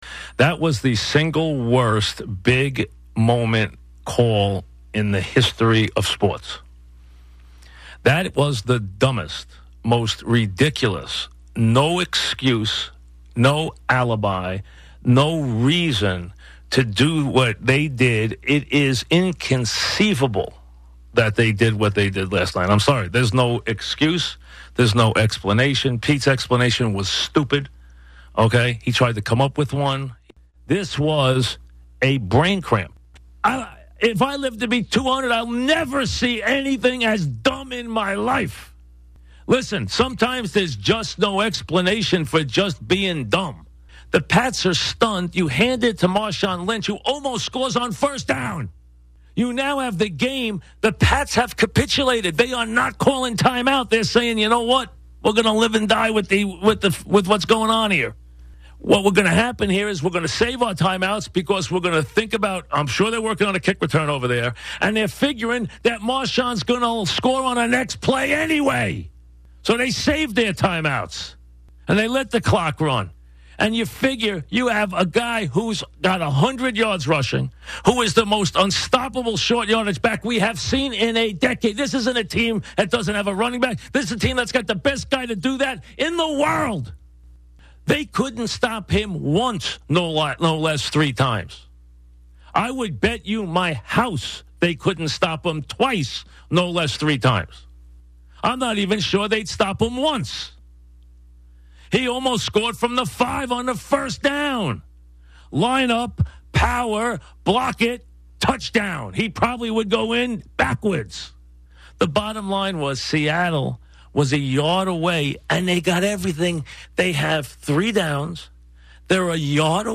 On Monday, Mike and the Dog gave similar takes during opening monologues on their respective radio shows two hours apart.